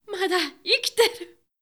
「喜」のタグ一覧
ボイス
女性